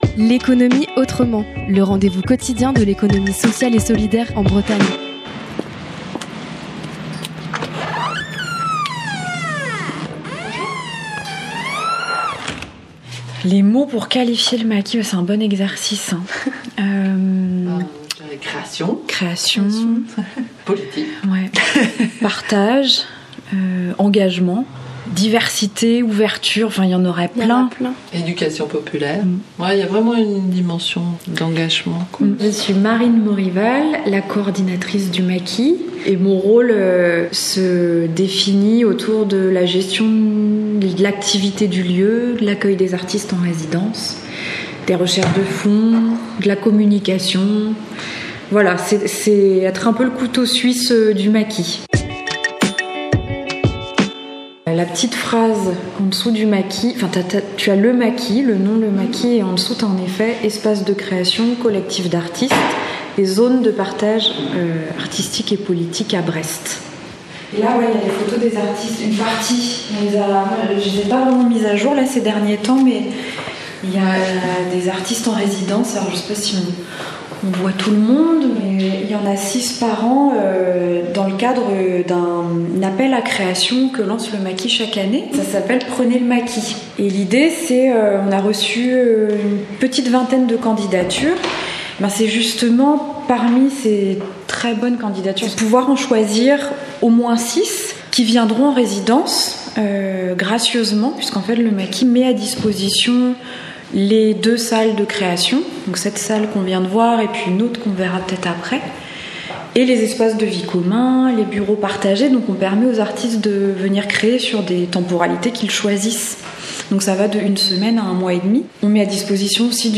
Cette semaine, La Corlab vous fait découvrir une nouvelle structure à travers les reportages " L’économie Autrement " !